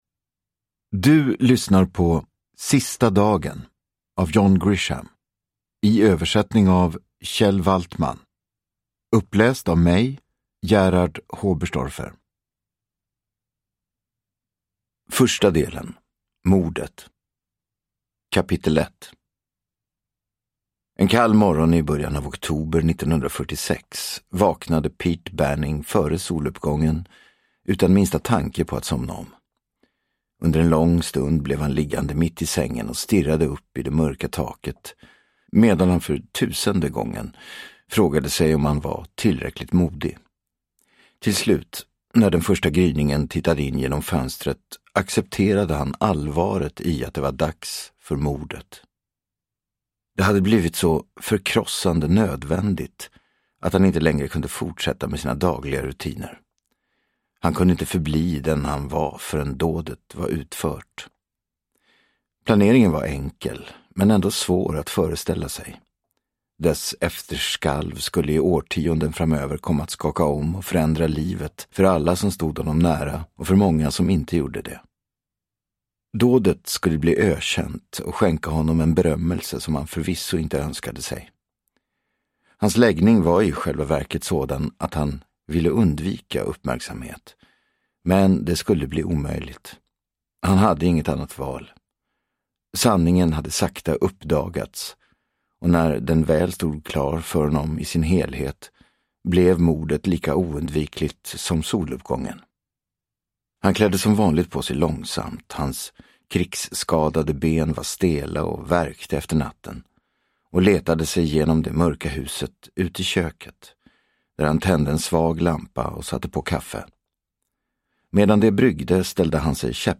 Sista dagen – Ljudbok – Laddas ner
Uppläsare: Gerhard Hoberstorfer